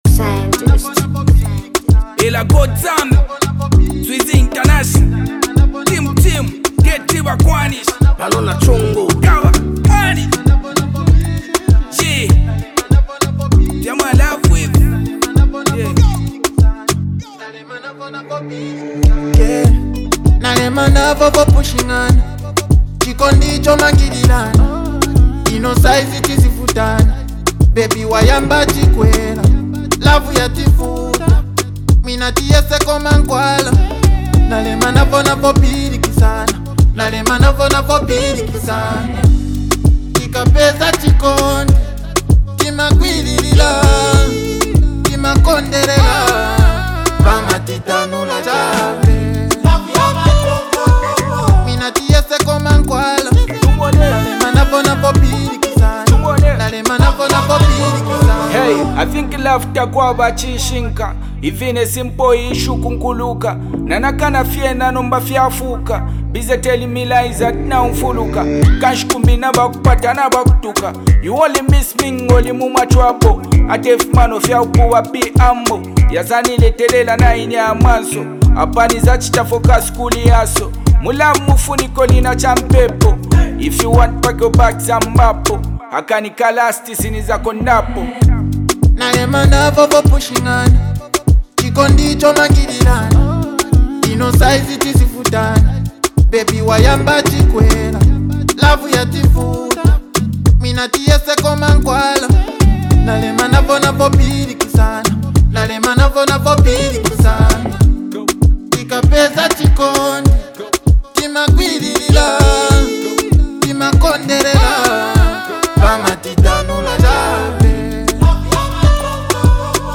delivers smooth vocals that carry the melody